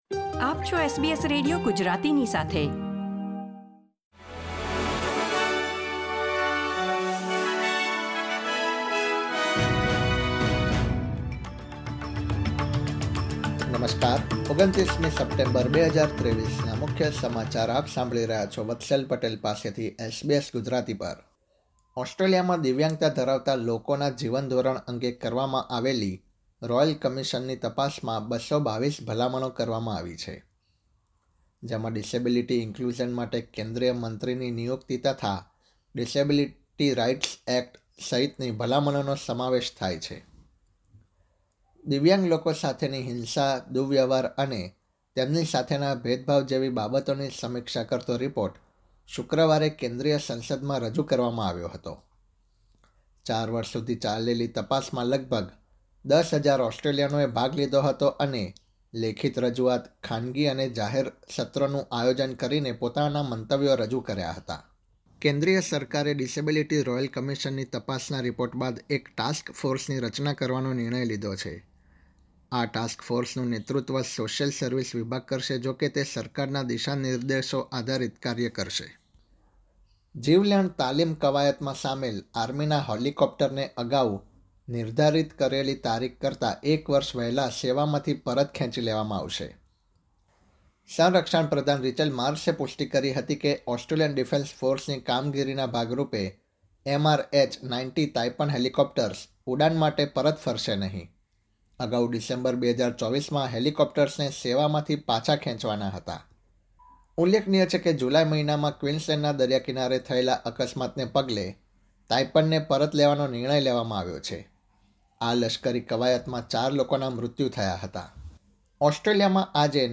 SBS Gujarati News Bulletin 29 September 2023